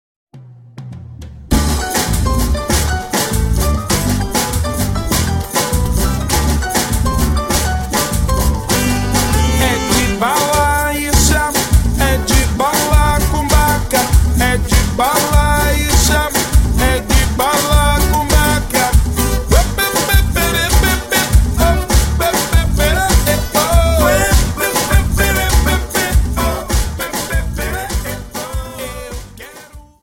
Dance: Samba